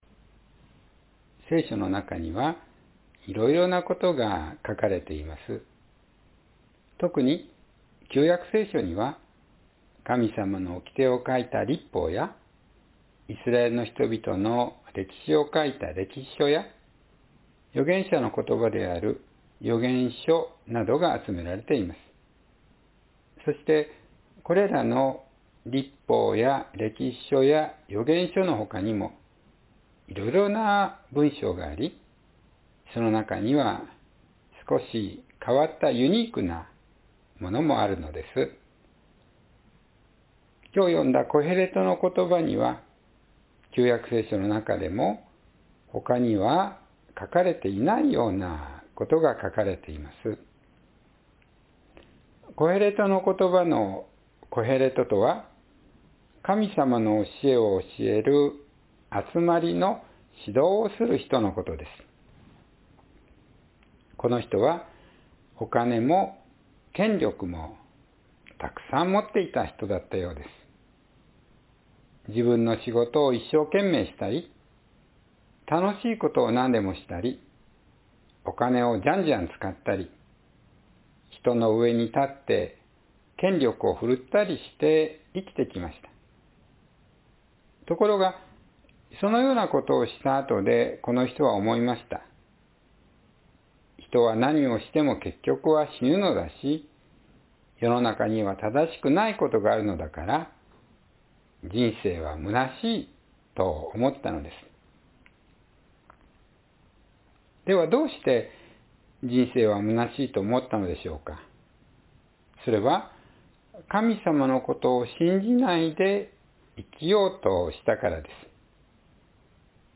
若い時に神様を知ろう“Remember God in the Days of Your Youth”（2024年11月24日・子ども説教） – 日本キリスト教会 志木北教会